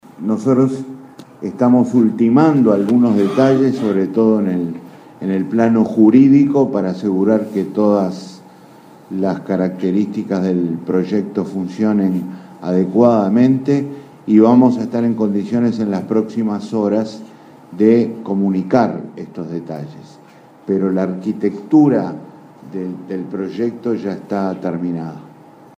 Además reveló en entrevista con la secretaría de Comunicación de la Presidencia que ANTEL, UTE, ANCAP, OSE y ANP proyectan una inversión pública por 3.000 millones de dólares en todo el período de gobierno.